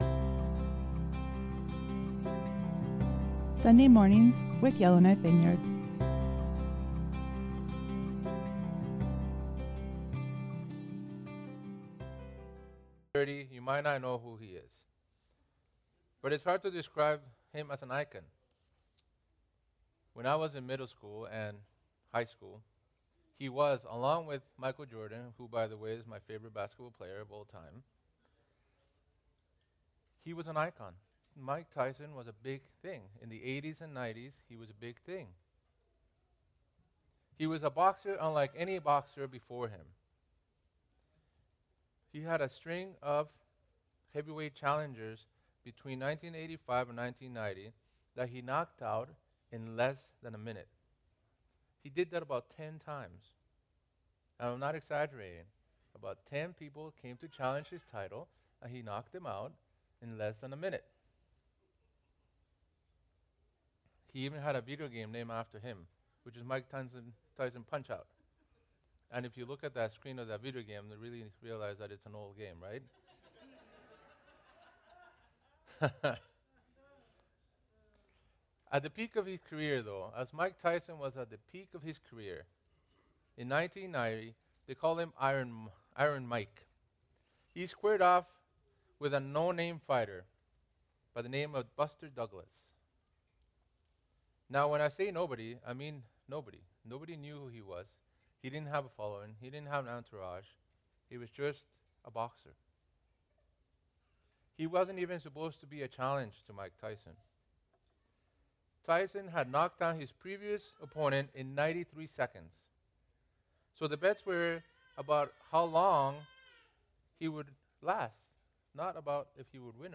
Sermons | Yellowknife Vineyard Christian Fellowship